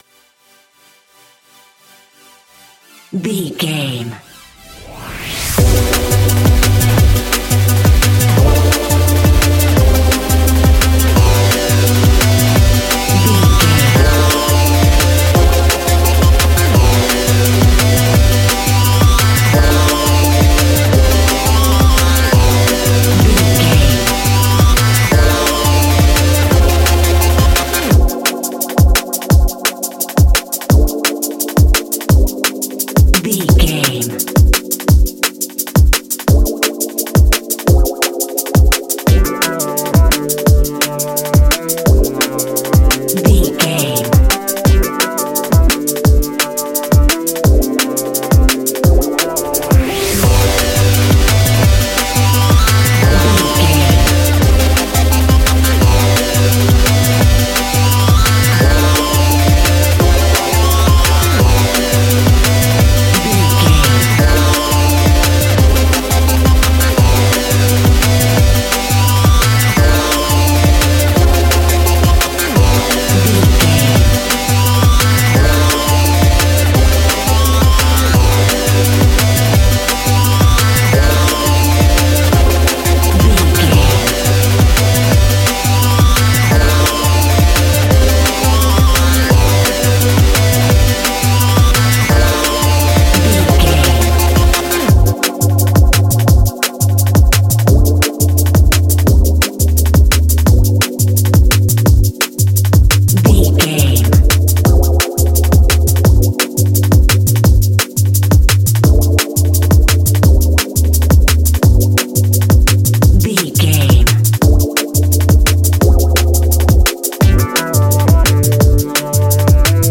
Ionian/Major
techno
trance
synths
synthwave